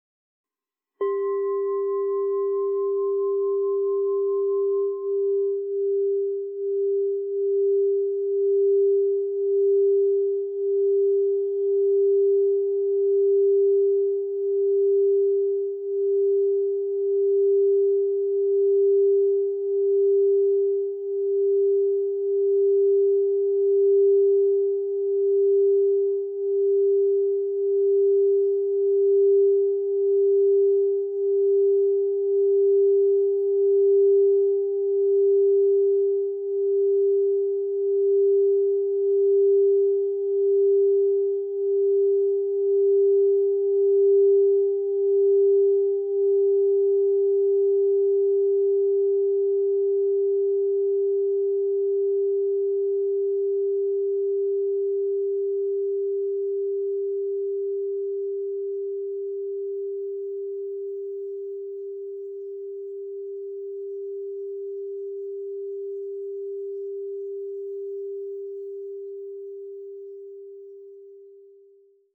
Meinl Sonic Energy 5.75" Essence Solfeggio Crystal Singing Bowl Ut 396 Hz, Olivgrün (ESOLCSB396)